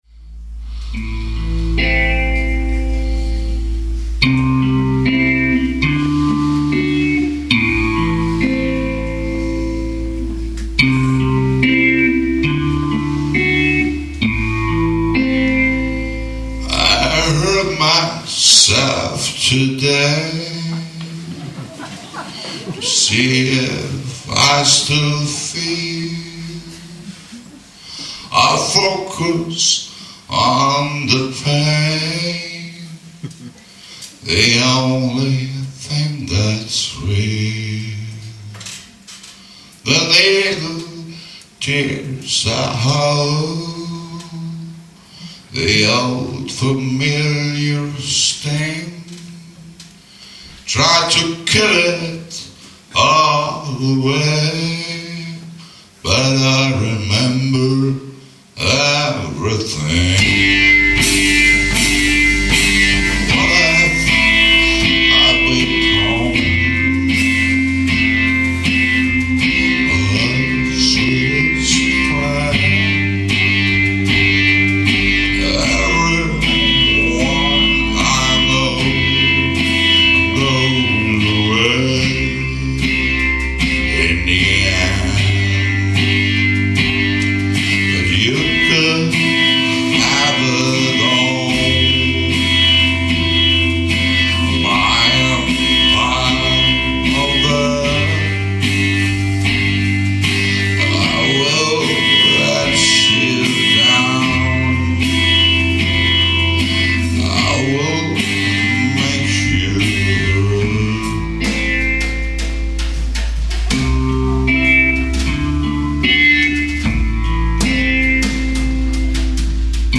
Ticino Theater 2007